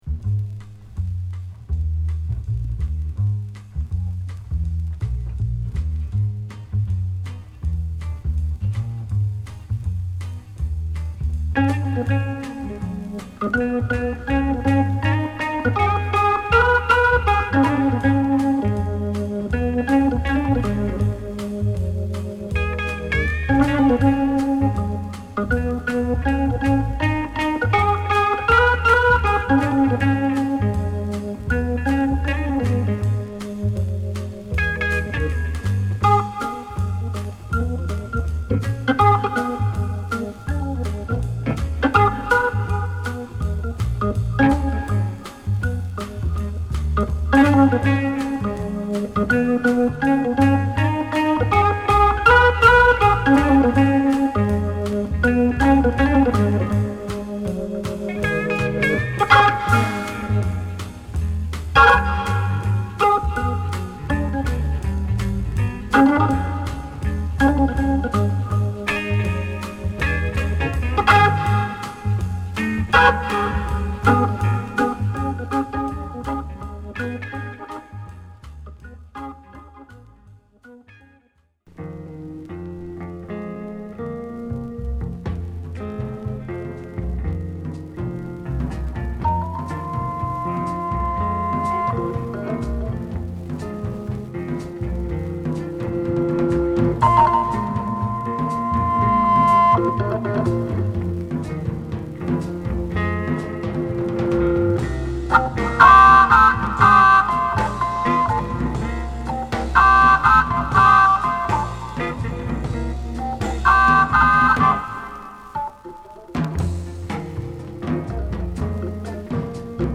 柔らかなオルガンプレイを聞かせる1枚！